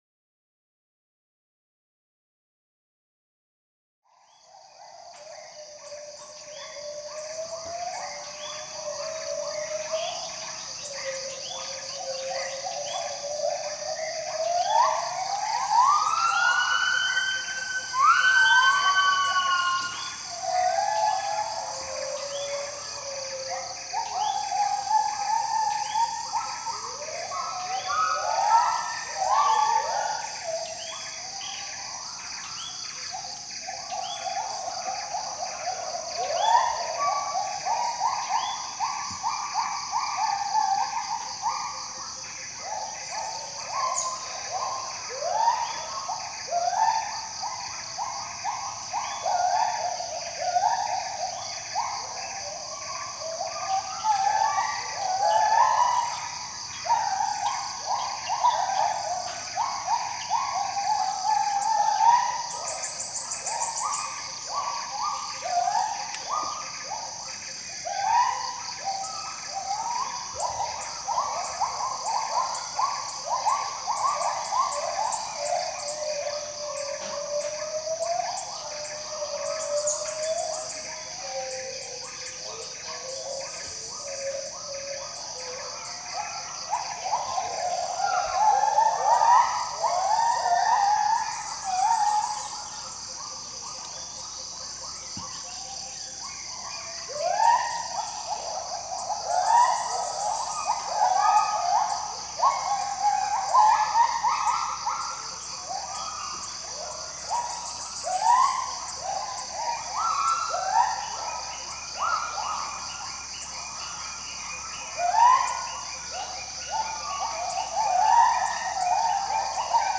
Chants gibbons
chants-gibbons.mp3